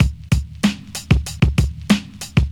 • 95 Bpm 2000s Hip-Hop Drum Groove C# Key.wav
Free breakbeat sample - kick tuned to the C# note. Loudest frequency: 793Hz